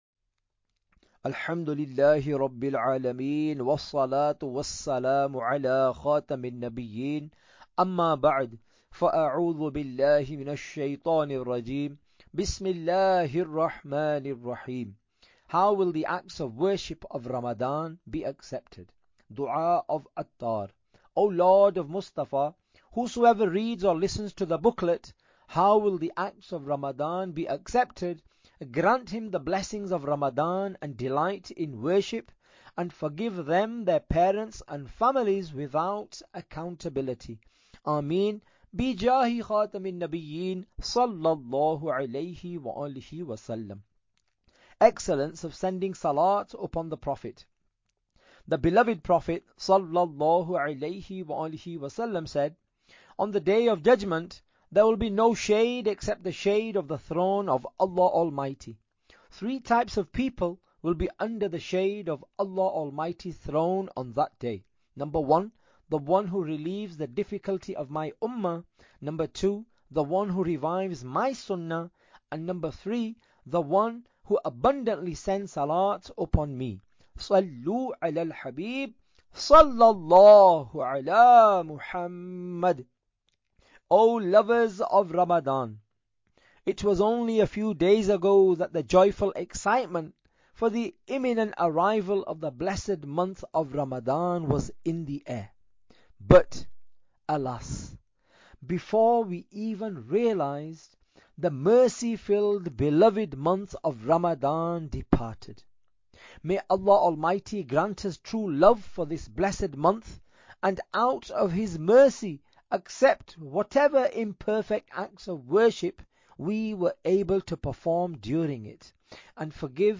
Audiobook - How Will The Acts of Worship of Ramadan be Accepted?